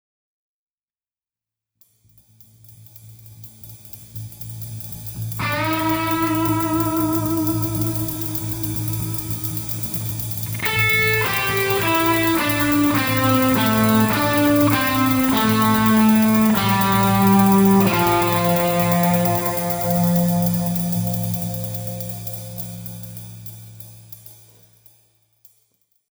One fun thing to do is to memorize a favorite guitar solo, learn to sing it in its entirety and, instead of just singing the pitches, recite the interval names as you sing. FIGURE 3 illustrates an example of this approach.